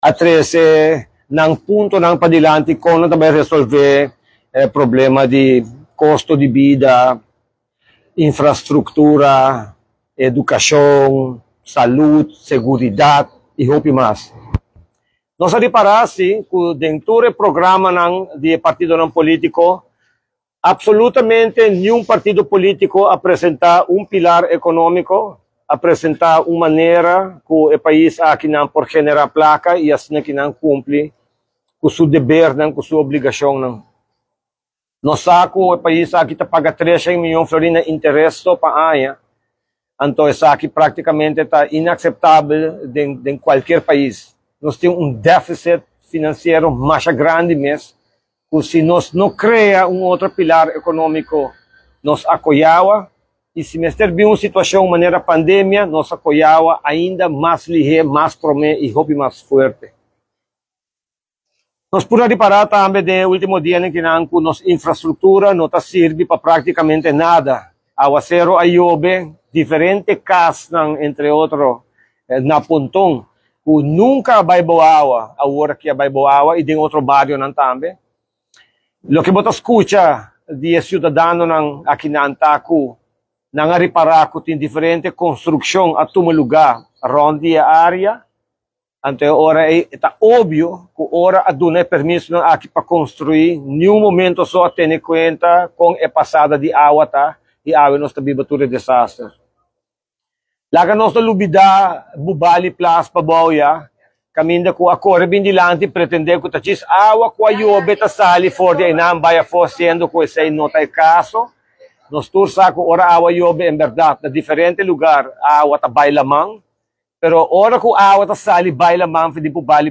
Lider di partido RED Ricardo Croes den un conferencia di prensa ta indica cu mayoria partido a trece padilanti con nan lo resolve costo di bida, sinembargo- no ta mira cu ta papia pa crea un di dos pilar economico. Ricardo Croes ta di opinion cu si Aruba no crea un otro pilar economico Aruba lo ta den un problema serio y si mester bin un situacion manera e pandemia un biaha mas Aruba lo ta mas critico ainda, pesey mes ta importante pa trece un di dos pilar economico pa Aruba.